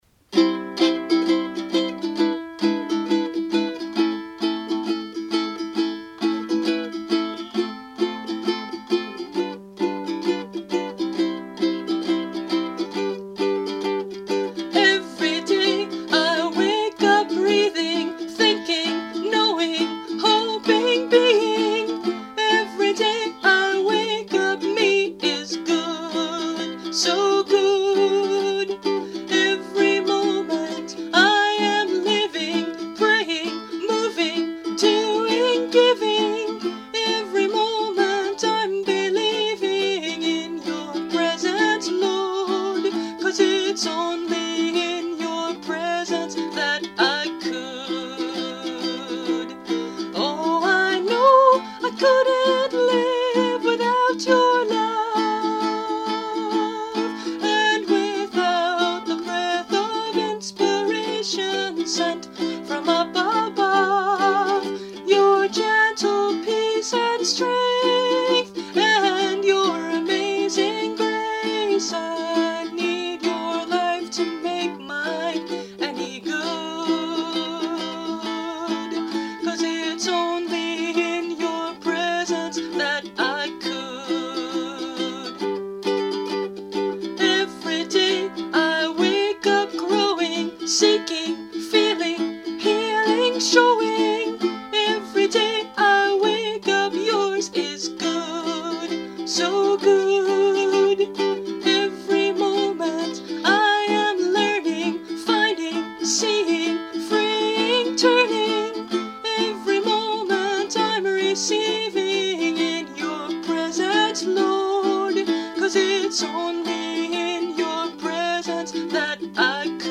Listen for the sound of an African grey parrot in this recording. 🙂